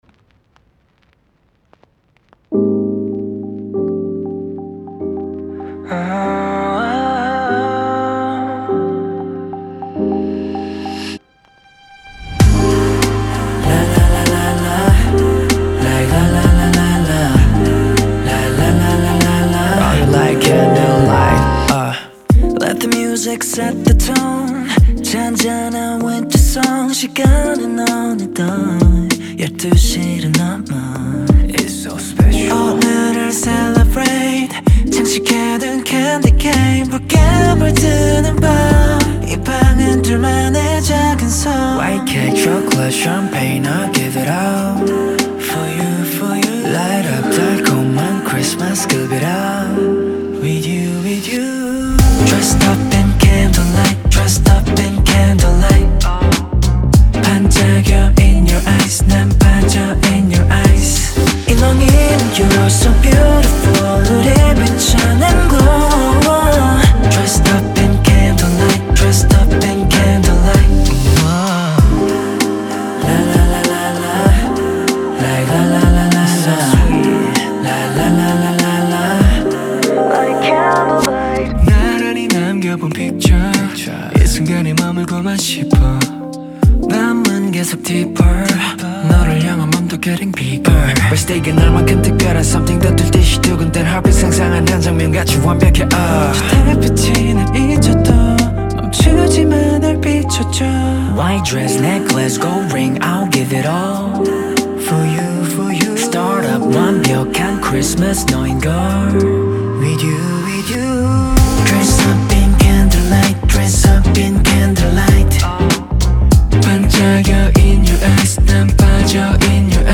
• Жанр: K-pop